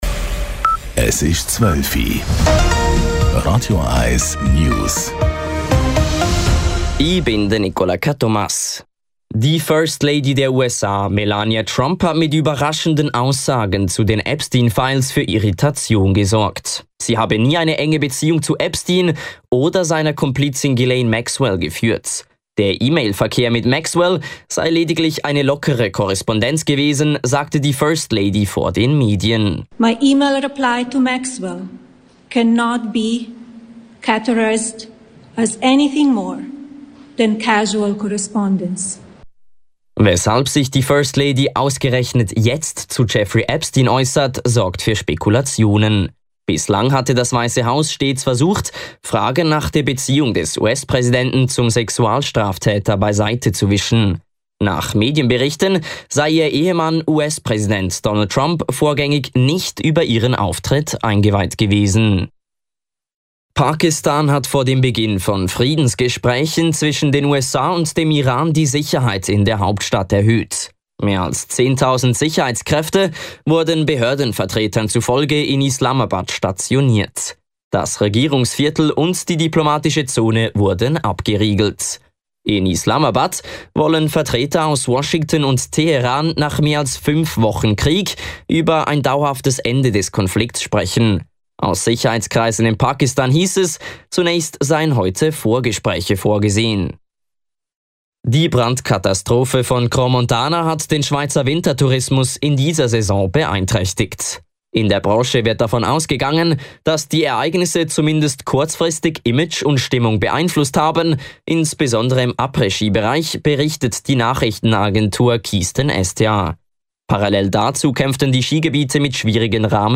Nachrichten & Politik